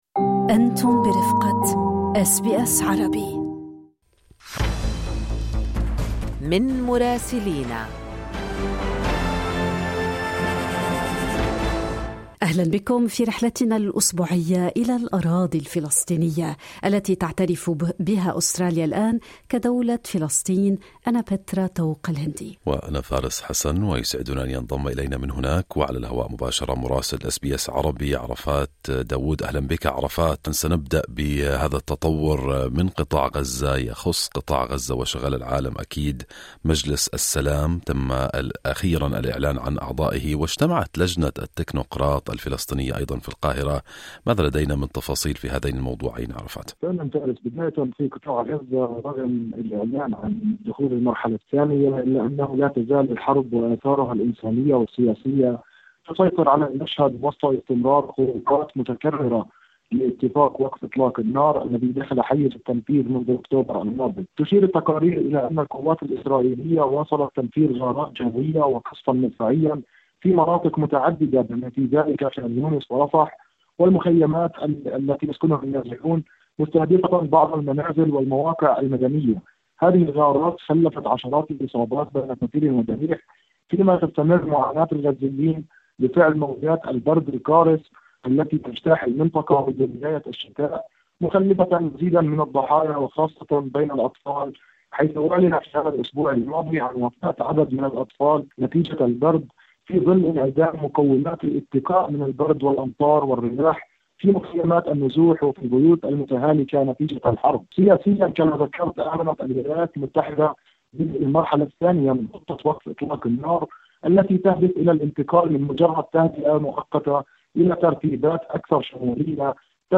من مراسلينا: تفاصيل مجلس السلام الدولي في غزة.. تفاقم الأوضاع الإنسانية هناك والمزيد من رام الله